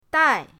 dai4.mp3